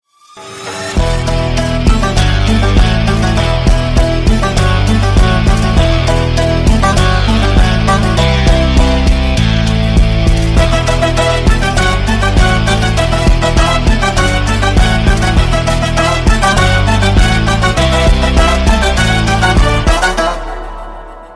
BGM Ringtones